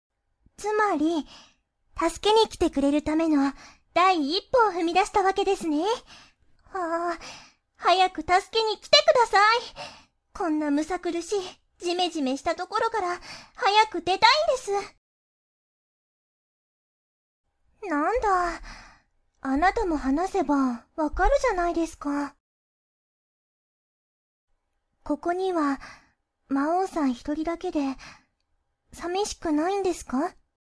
敬語キャラ。
なんかおどおどしていて雰囲気に流されやすい。